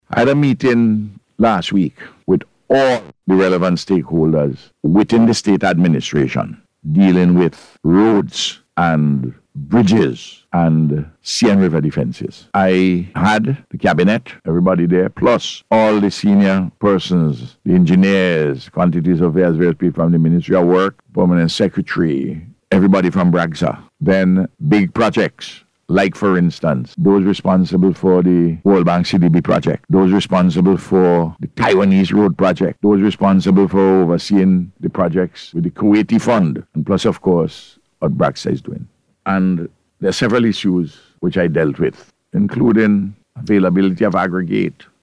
This statement was made by Prime Minister Dr. Ralph Gonsalves during the Face to Face programme aired on NBC Radio.